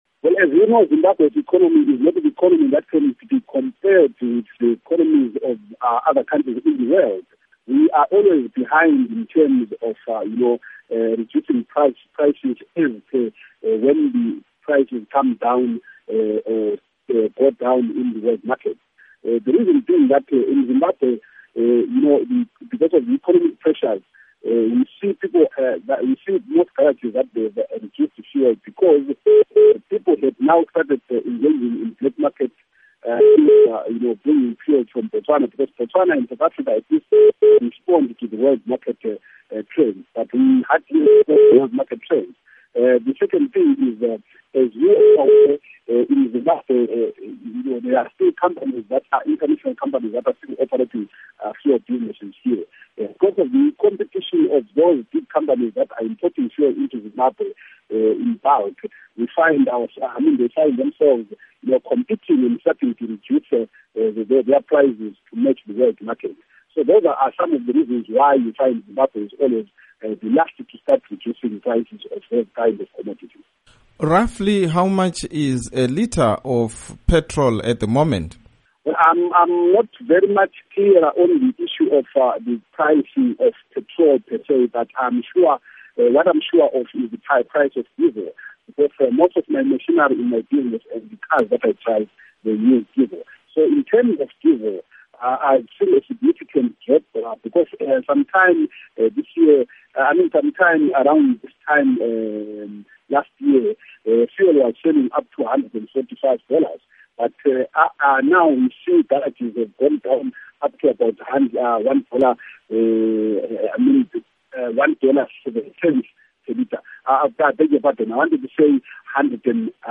Interview With Abednico Bhebhe on Fuel Prices